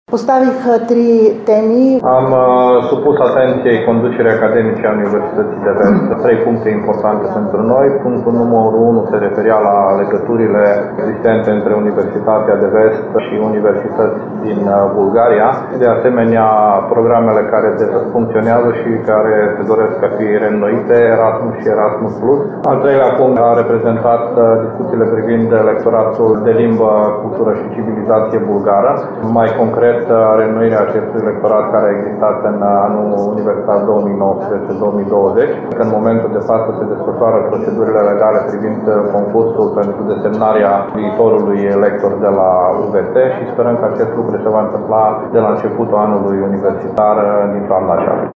Trei teme majore de colaborare au fost stabilite între universități bulgare și UVT, spune vicepreşedintele Republicii Bulgaria, Iliana Iotova.